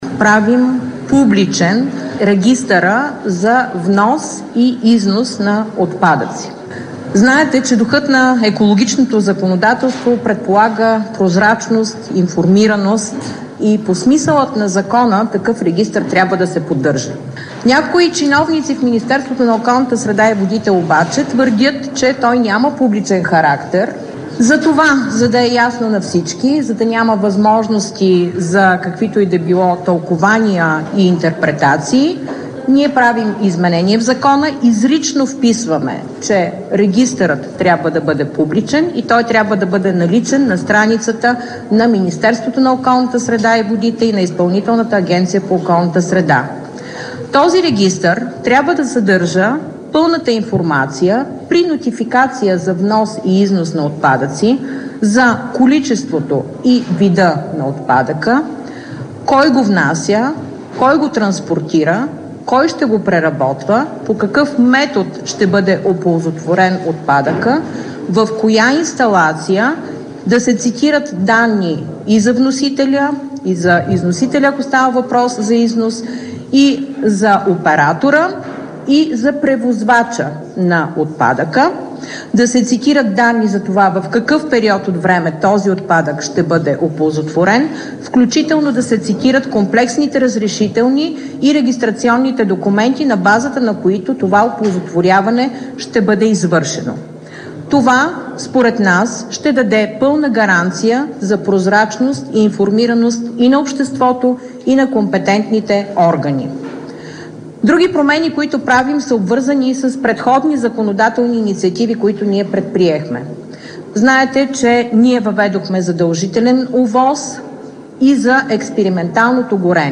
9.00 - Заседание на Народното събрание. - директно от мястото на събитието (Народното събрание)
Радио К2 директно от мястото на събитието